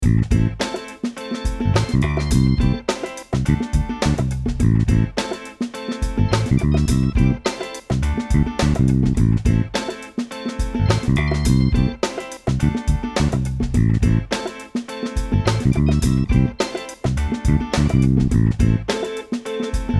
funk.wav